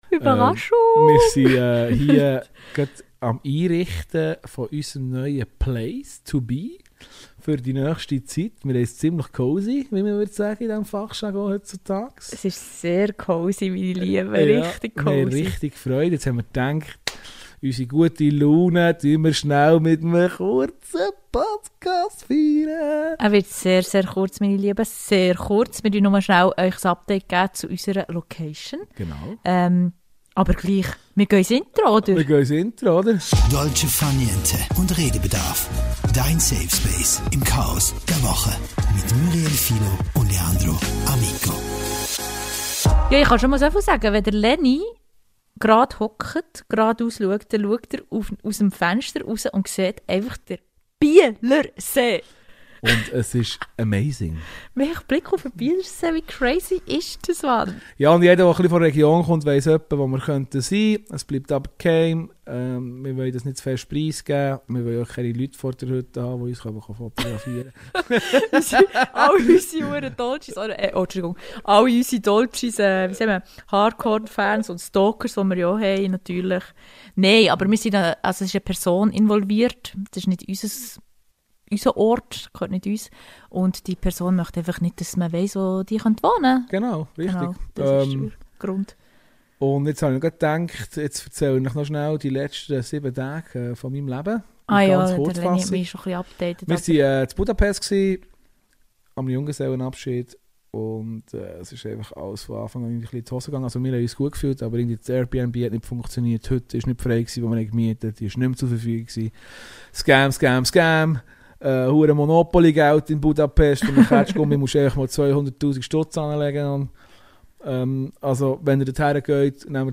Locker, ehrlich und so spontan wie selten – eben ein echter Amüs Busch.